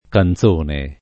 canzone [ kan Z1 ne ]